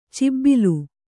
♪ cibbilu